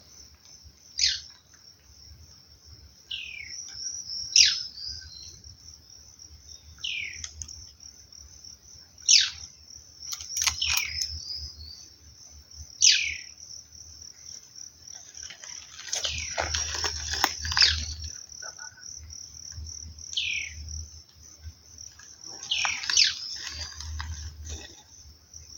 White-bearded Manakin (Manacus manacus)
Location or protected area: Pe da Serra do Tabuleiro--estrada Pilões
Condition: Wild
Certainty: Observed, Recorded vocal